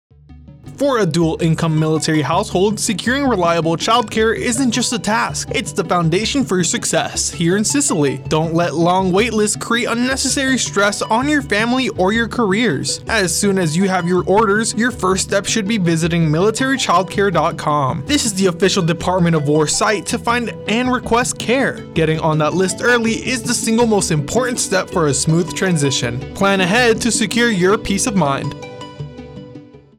NAVAL AIR STATION SIGONELLA, Italy (April 10, 2026) A radio spot that highlights the Dual Military Child Care program.